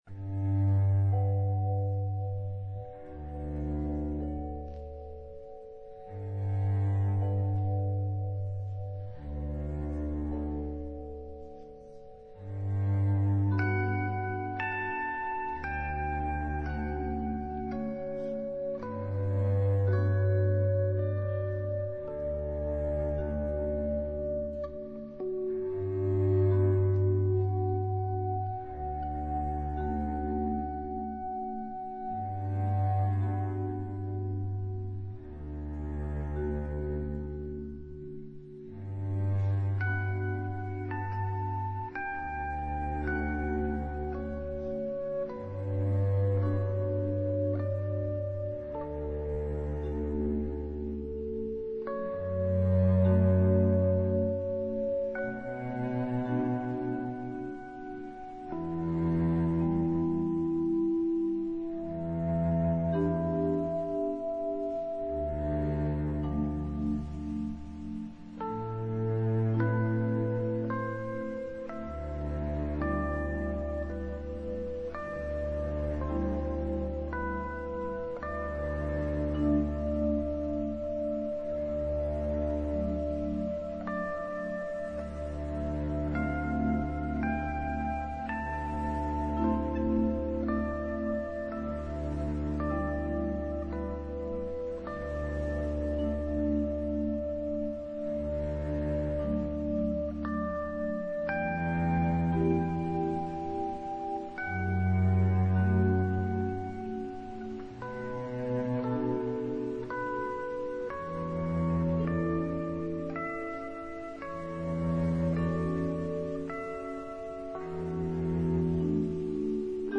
使用了傳統樂器與電子樂器
很容易聽出大提琴往下拉出看不透的空間感，
而電子樂猶如一顆顆亮起的星點，點出還有更多空間。